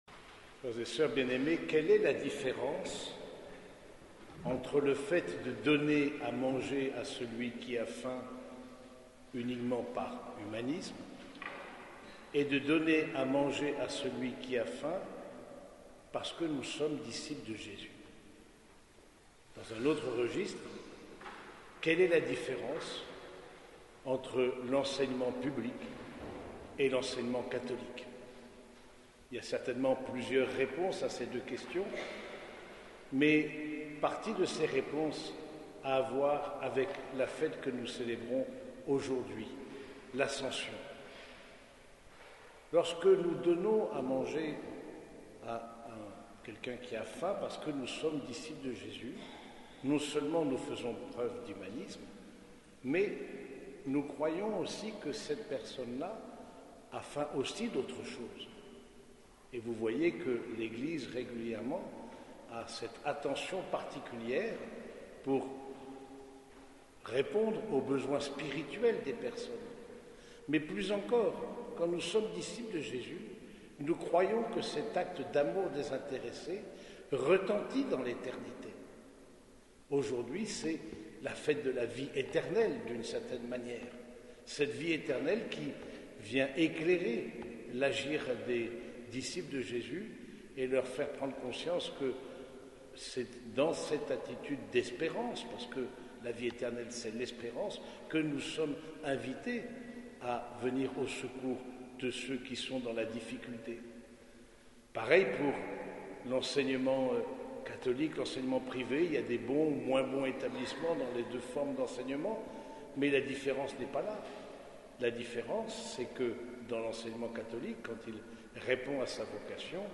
Cette homélie a été prononcée au cours de la messe dominicale à l’église Saint-Germain de Compiègne.